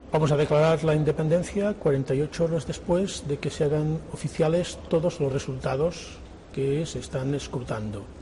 Carles Puigdemont en la entrevista publicada en la BBC